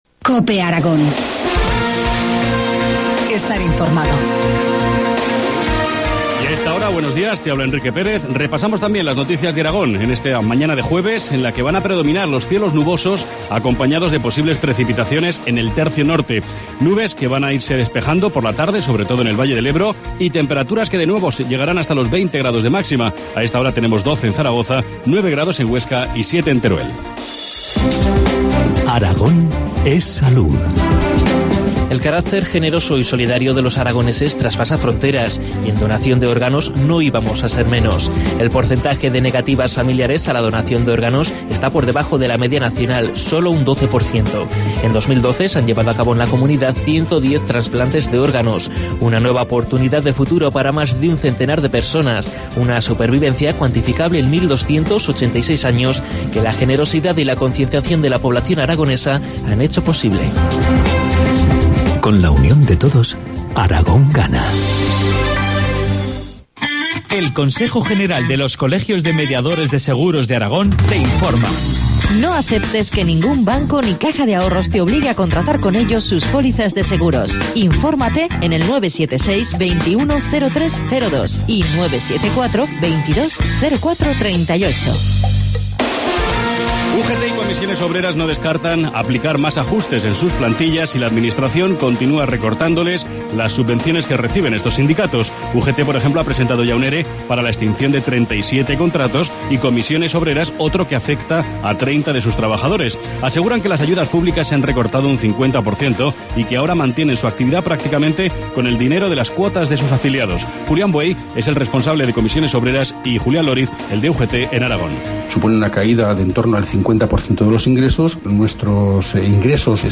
Informativo matinal, jueves 7 de marzo, 8.25 horas